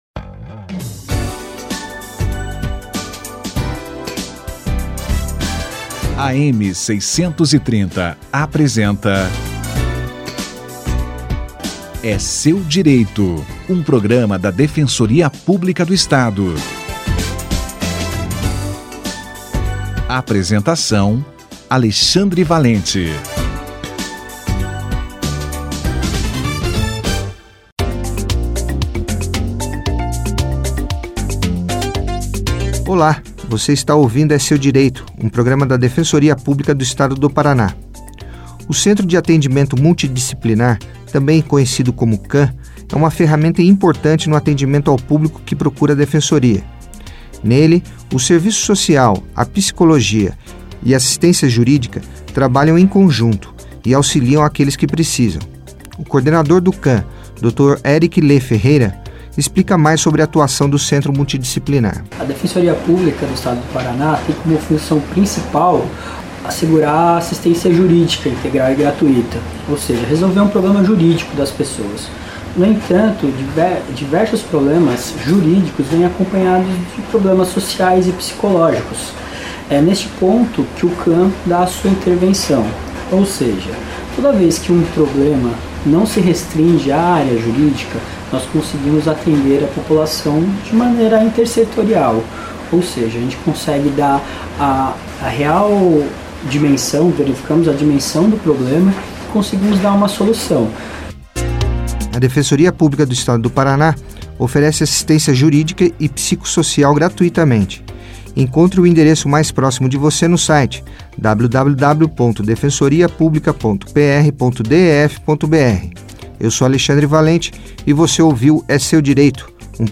26/10/2018 - Centro de Atendimento Multidisciplinar (CAM) no atendimento à população. Entrevista